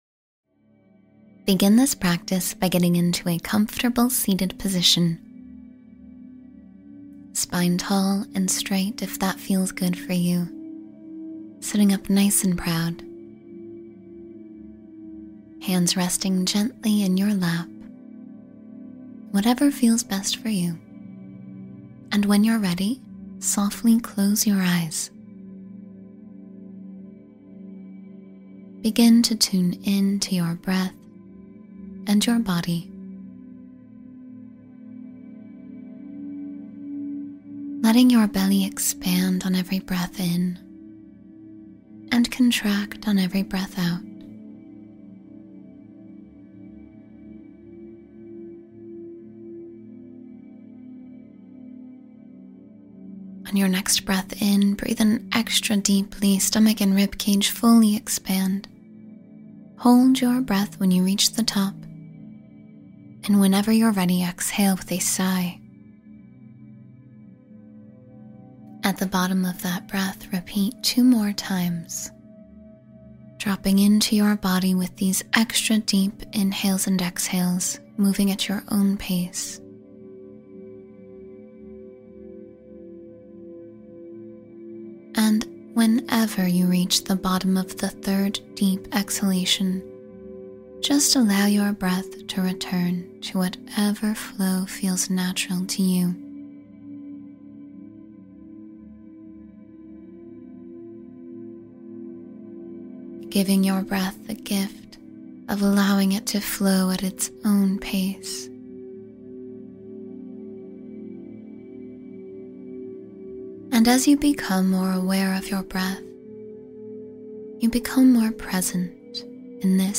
Reflect on How Far You’ve Come — Guided Meditation for Gratitude